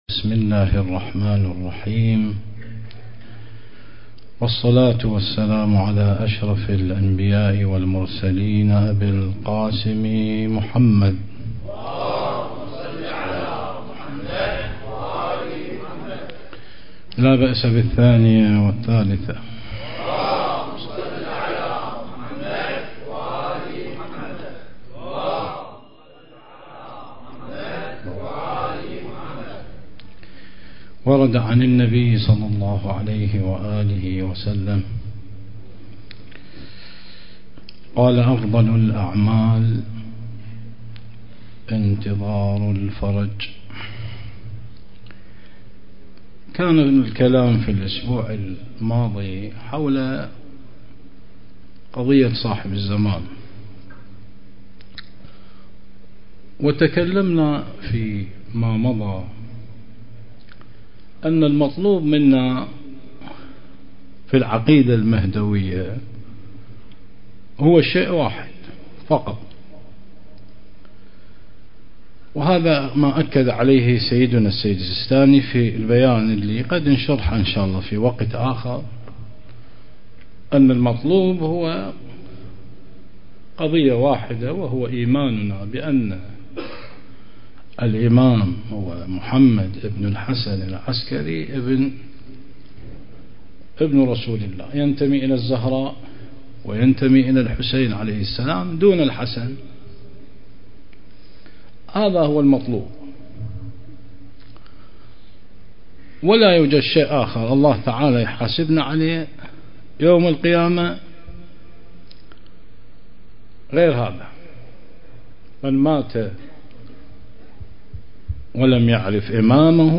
المجلس الحسيني ليوم 19 شعبان ١٤٤٥هـ